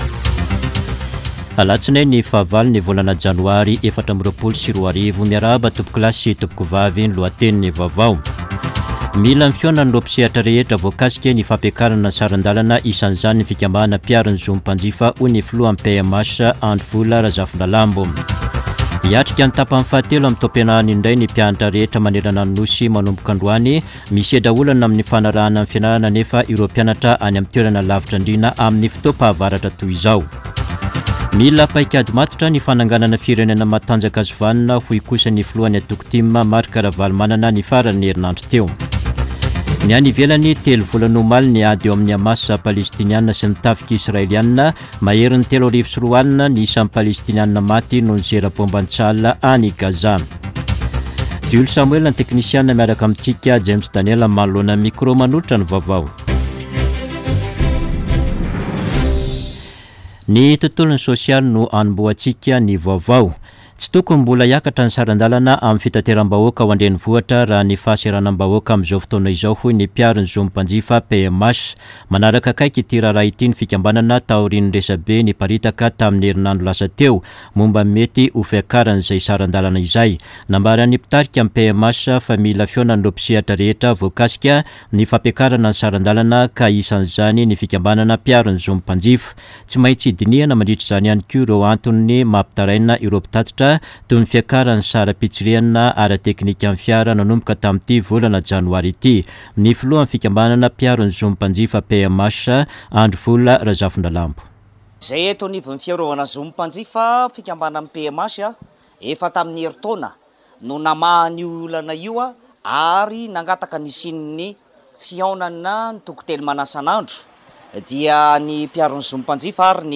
[Vaovao maraina] Alatsinainy 8 janoary 2024